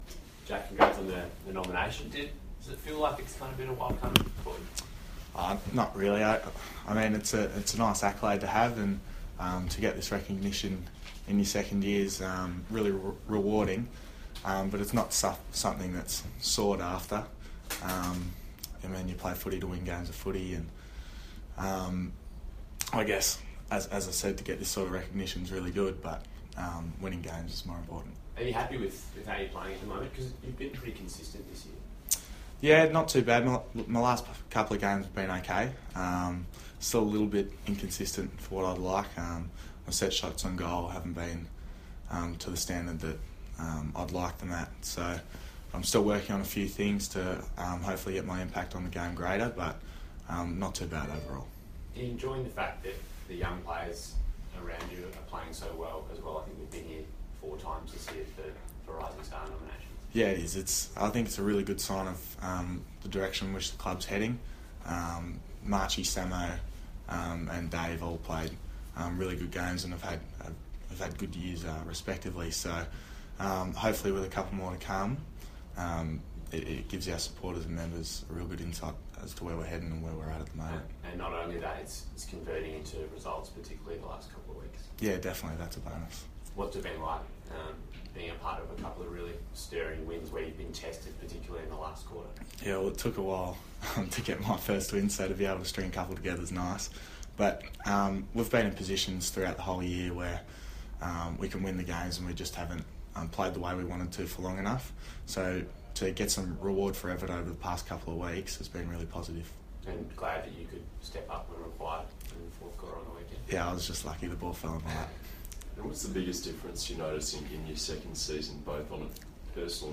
Jack Silvagni press conference - June 20
Jack Silvagni faces the media after his Round 13 Rising Star nomination.